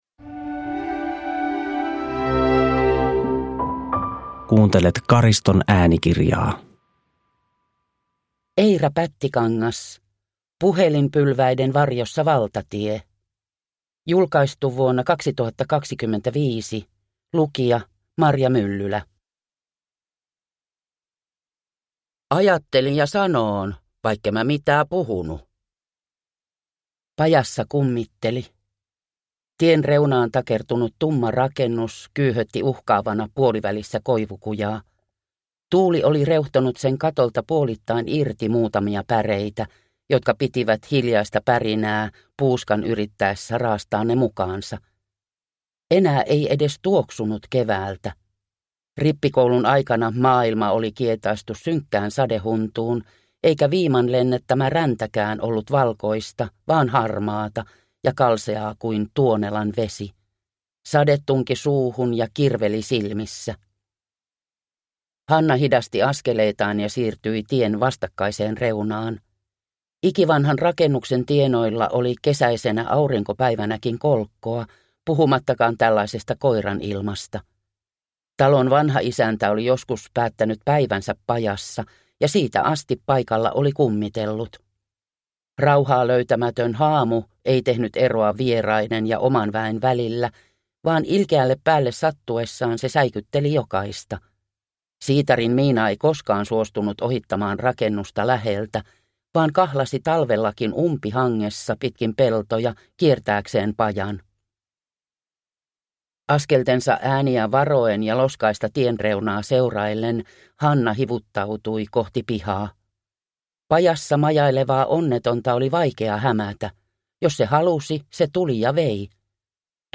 Puhelinpylväiden varjossa valtatie (ljudbok